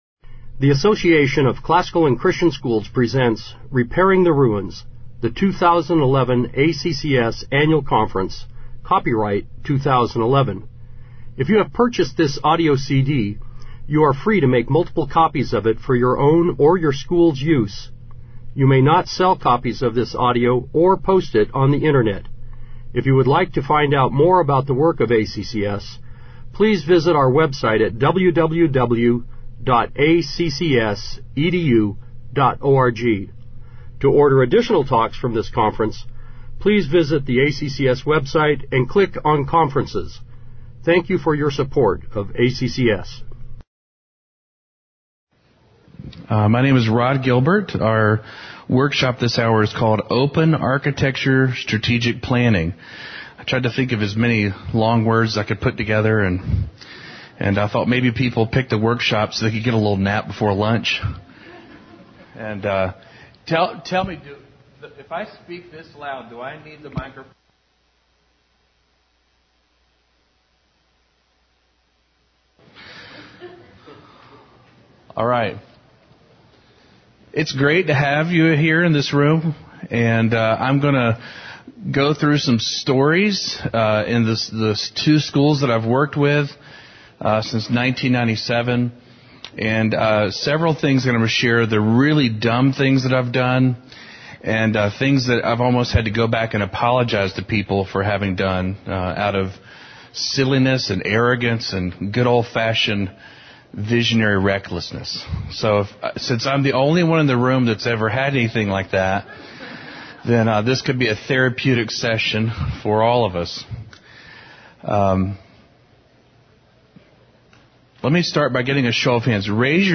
2011 Workshop Talk | 0:54:50 | All Grade Levels, Leadership & Strategic, Operations & Facilities